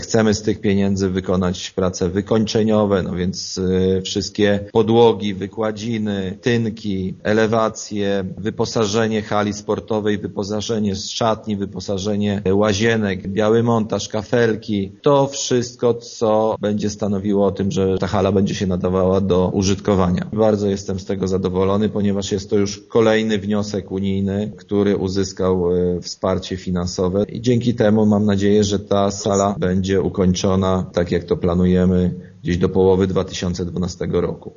Zarząd Województwa Lubelskiego, który dzieli te środki właśnie ogłosił, że otrzymamy na ten cel blisko 400 tysięcy złotych” - mówi burmistrz Kazimierza Grzegorz Dunia: